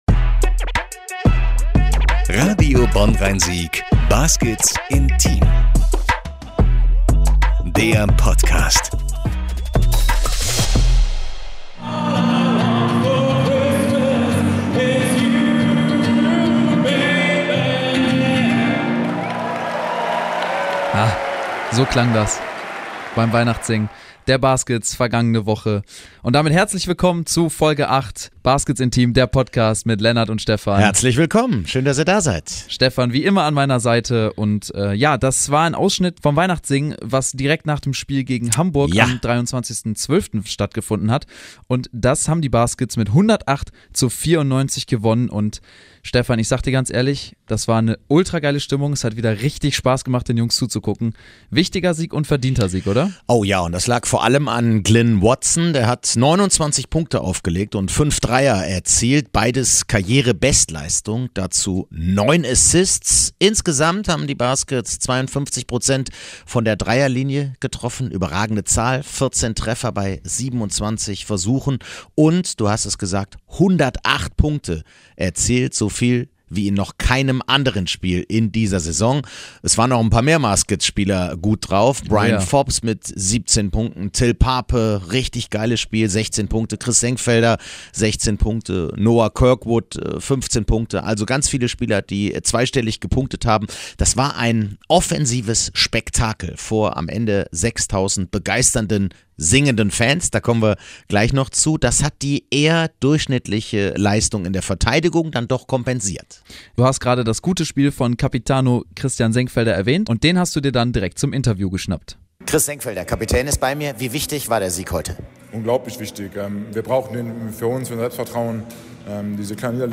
Ein spektakulärer Sieg der Baskets war das, am Abend vor Weihnachten, gegen die Hamburg Towers. Anschließend wurden Weihnachtslieder gesungen im Telekom Dome.
Noch mehr weihnachtliche Stimmung, noch mehr singende Spieler, noch mehr Fans und noch mehr zu den Baskets, hört ihr in der neuen Folge in der neuen Episode, klickt euch rein!